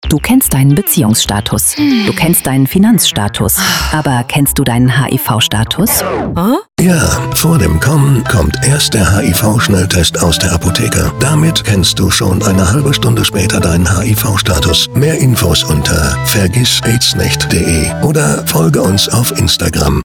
Radio Spot hier downloaden - Länge 30 Sekunden
Funk+Spot.mp3